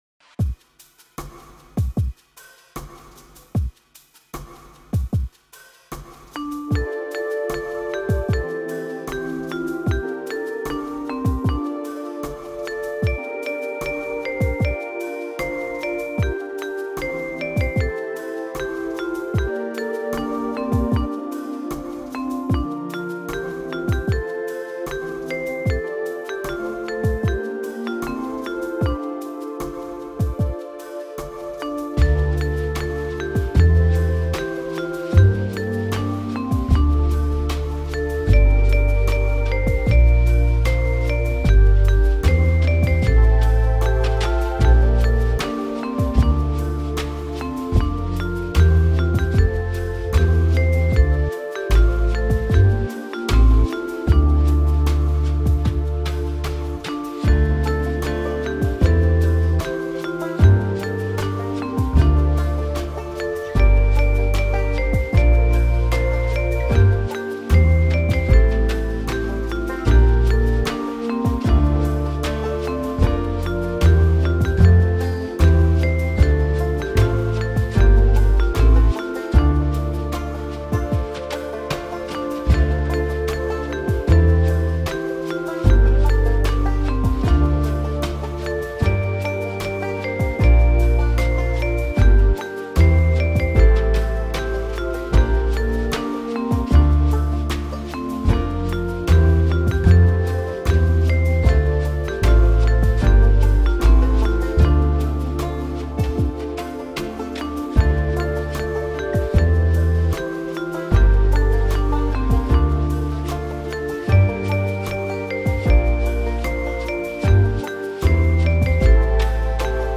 A Vietnamese hymn